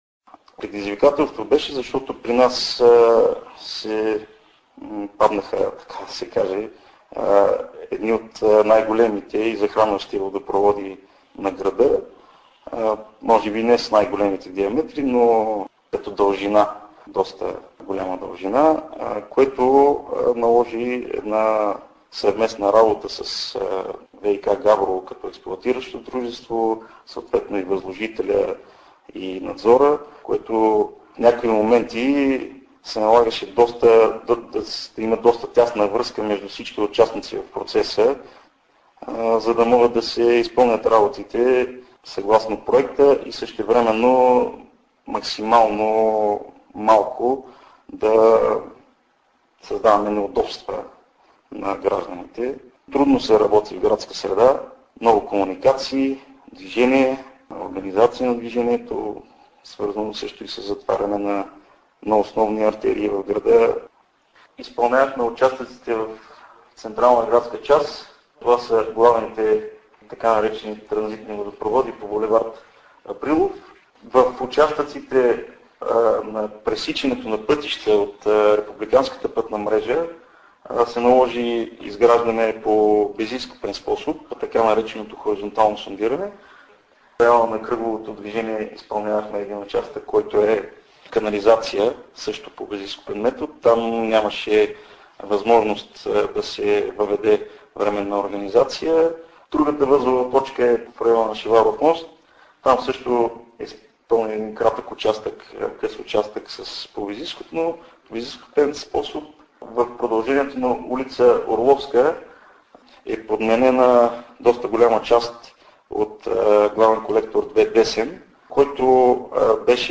Intervu_Etap_2_za_radio.mp3